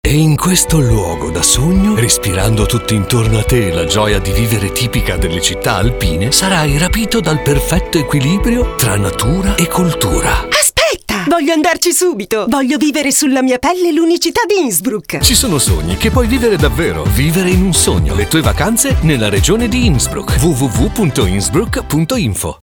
Radiospot Winter D-A-CH